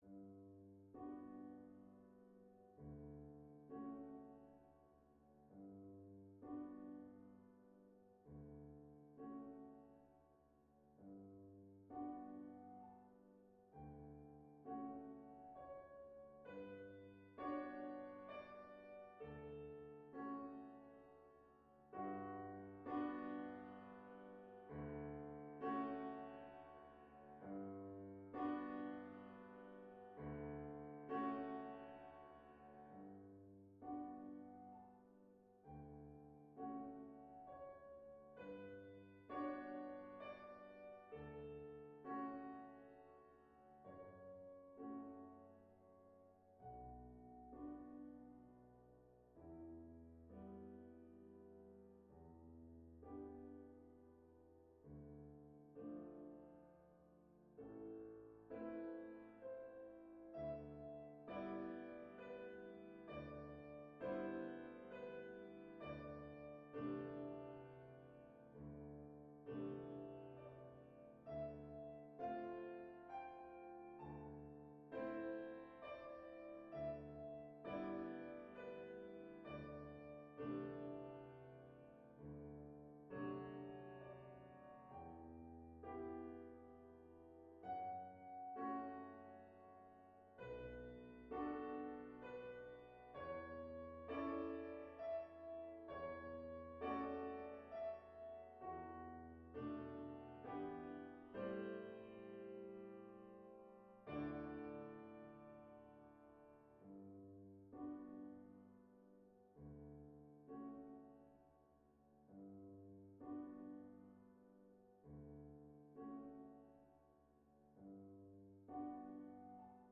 まずは冒頭部、旋律部が非常に明確で華やかです。さらに、音の長さが安定的でゆったりしています。
しかし変化の中間部、音調は少し低くなり重々しさが増します。
そして後半部、再度冒頭の澄んだメロディに戻ることで、なんともいえない安定感と余韻に包まれます。
・ゆっくりなテンポ
・リズムに乗りやすい３拍子